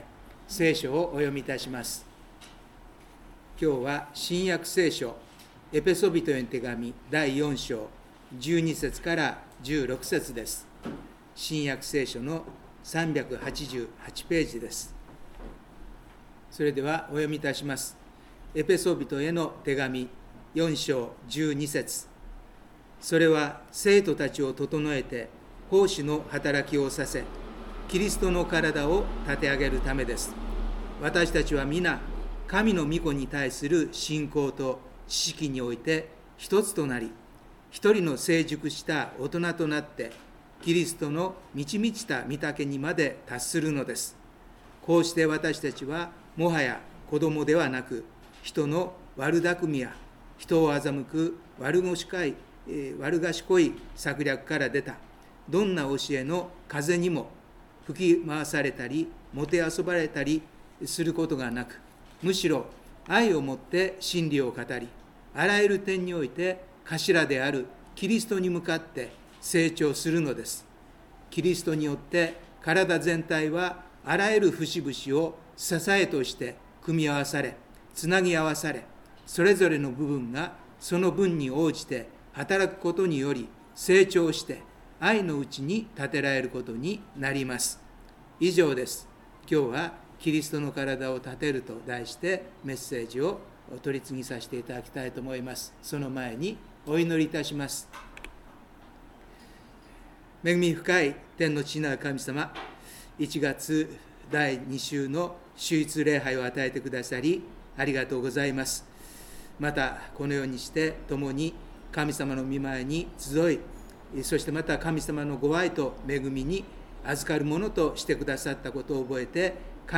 第二主日礼拝